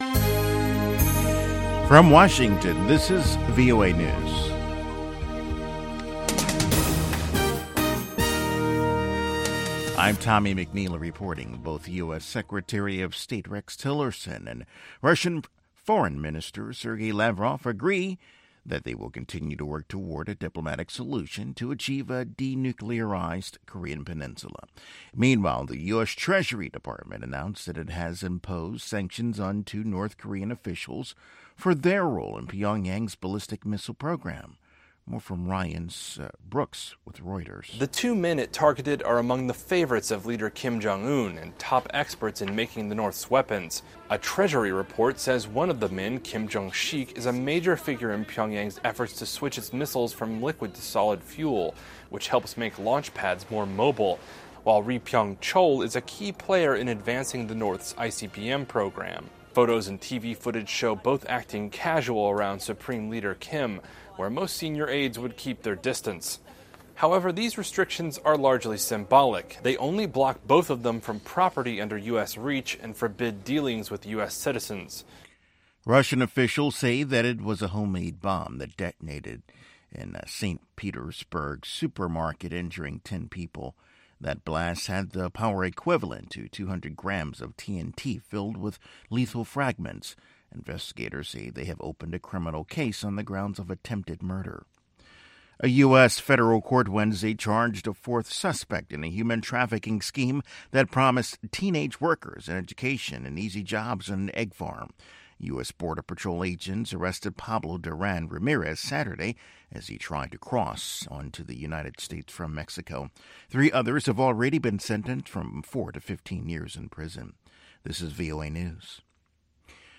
contemporary African music and conversation